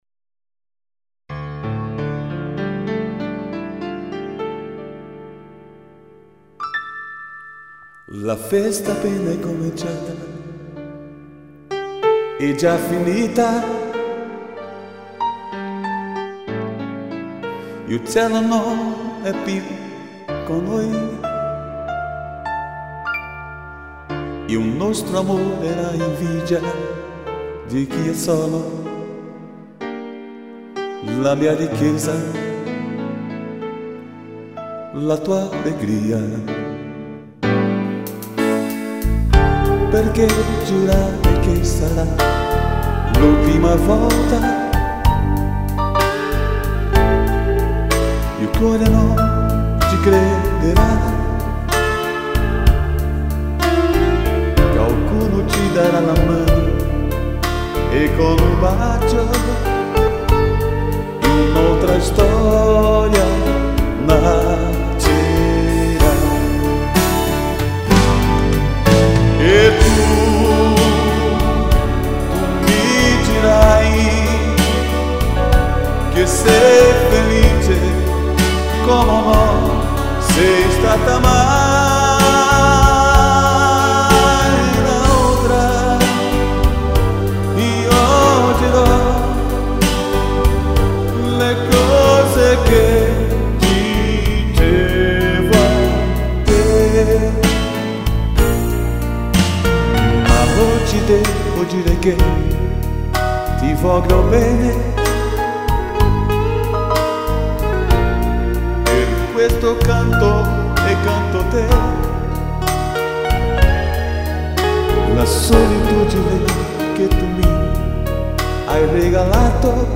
EstiloRomântico